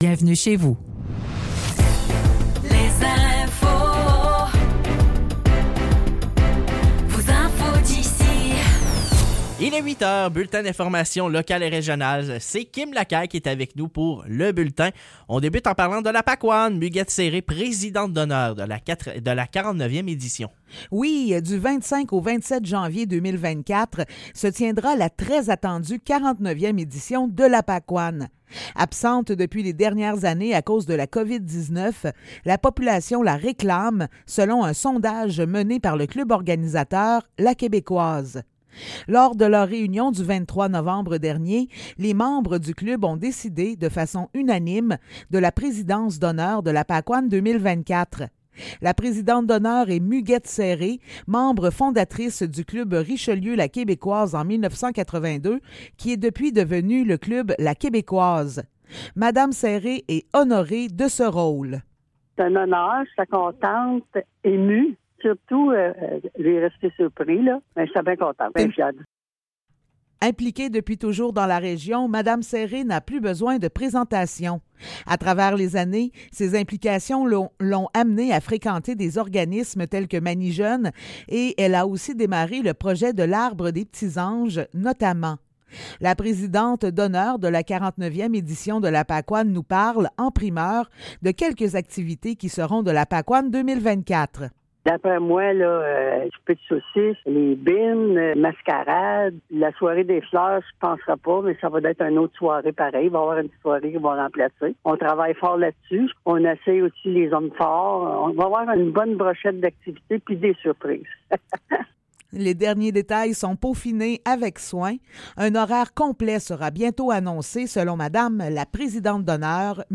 Nouvelles locales - 13 décembre 2023 - 8 h